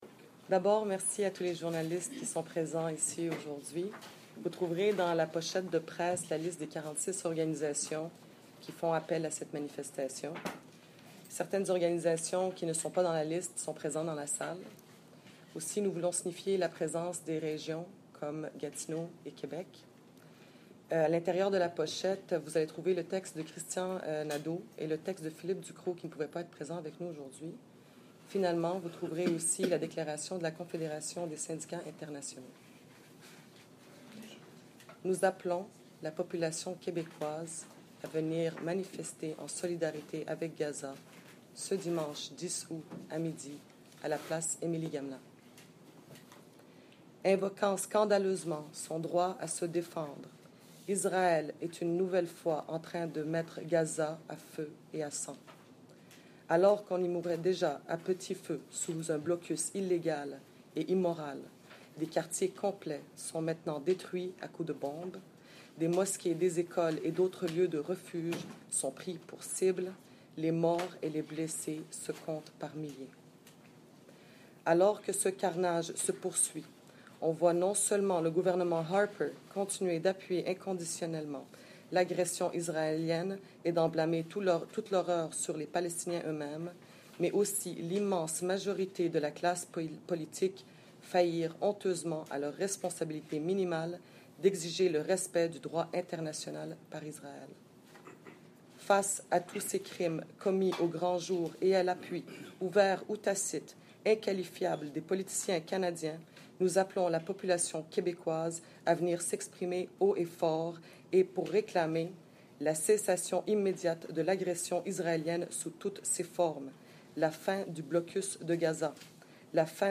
Conférence de Press appelant les Québecois à soutenir le Peuple Palestinien
File Information Listen (h:mm:ss) 0:39:35 Conférence de Press: Solidarité Québec-Gaza Download (6) Quebec_Gaza_Press_Conference_-_2014-08-08.mp3 37,998k 0kbps Stereo Listen All